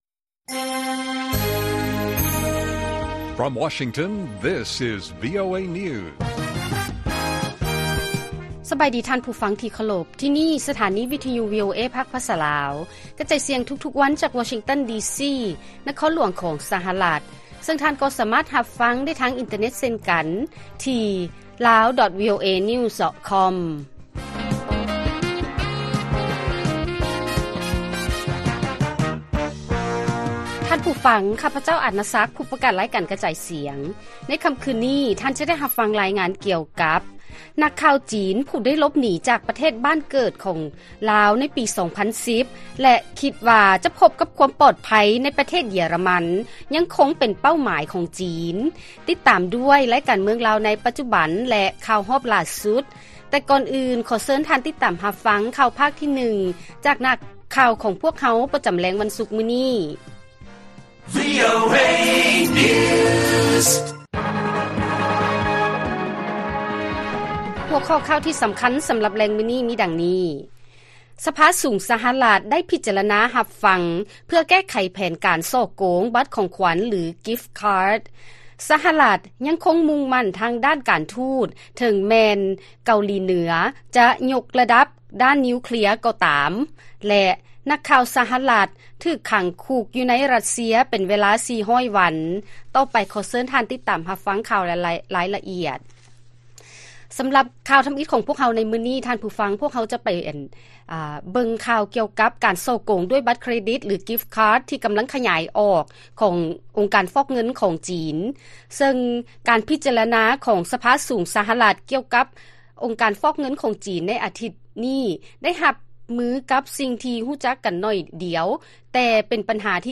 ລາຍການກະຈາຍສຽງຂອງວີໂອເອລາວ: ສະພາສູງ ສະຫະລັດ ໄດ້ພິຈາລະນາຮັບຟັງ ເພື່ອແກ້ໄຂແຜນການສໍ້ໂກງບັດຂອງຂວັນ ຫຼື gift card